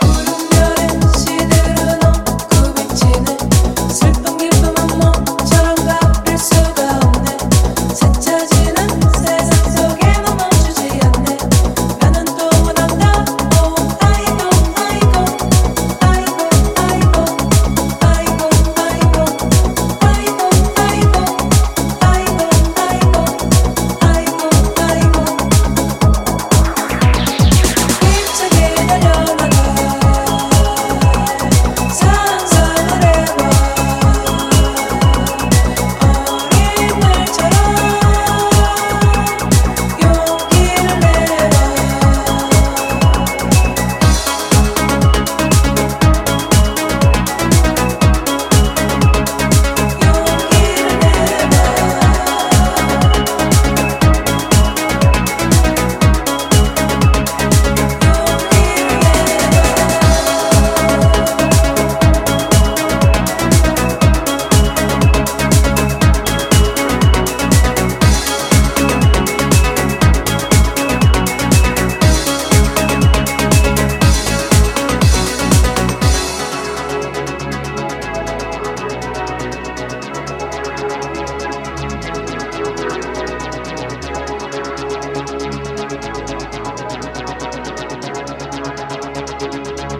club-focused electronic music